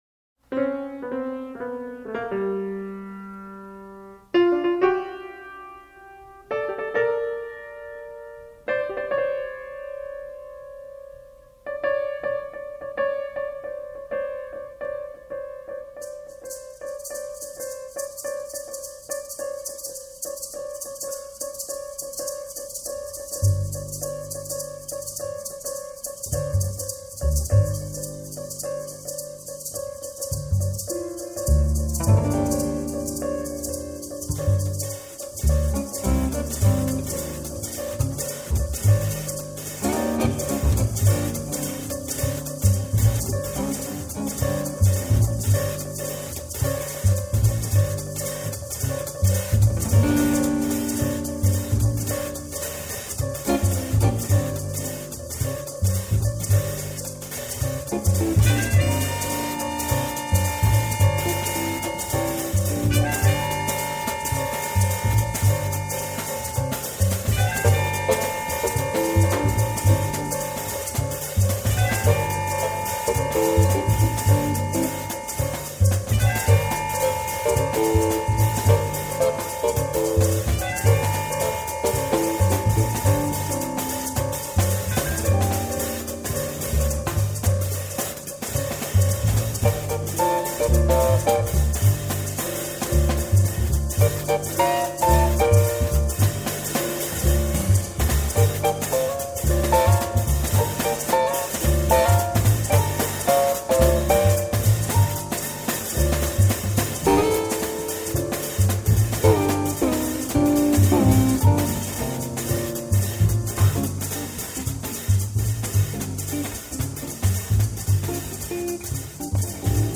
یکی از شاهکارهای جاز ارکسترال است